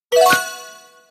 reward.ogg